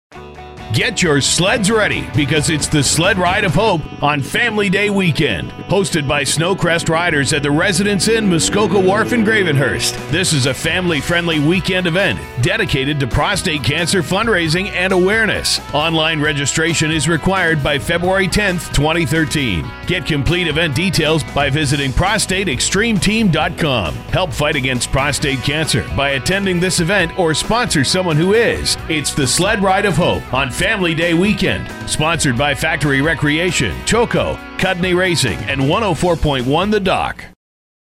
2013 The Dock Sled Ride Radio Ad
2013_Sled_Ride_of_Hope_DOCK.mp3